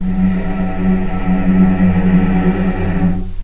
healing-soundscapes/Sound Banks/HSS_OP_Pack/Strings/cello/ord/vc-F#2-pp.AIF at cc6ab30615e60d4e43e538d957f445ea33b7fdfc
vc-F#2-pp.AIF